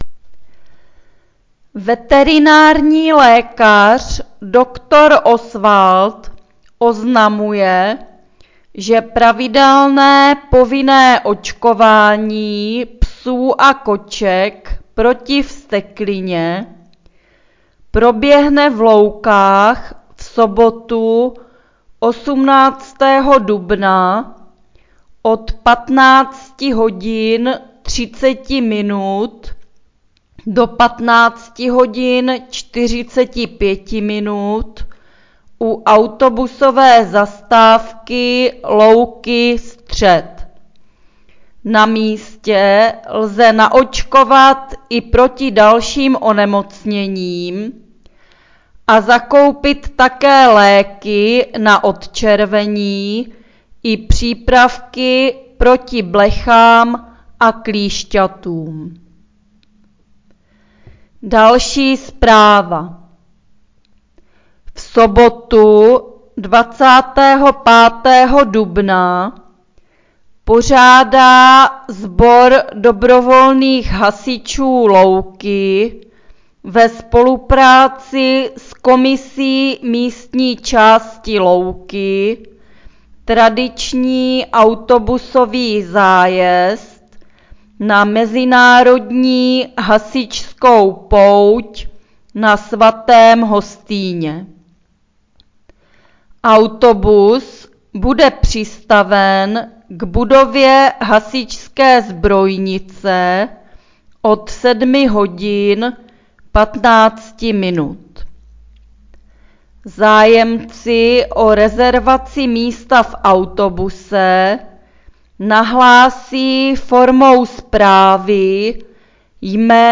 Hlášení místního rozhlasu
Samotné hlášení provádí pracovníci kanceláří místních částí ze svých pracovišť.